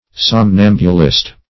Somnambulist \Som*nam"bu*list\, n.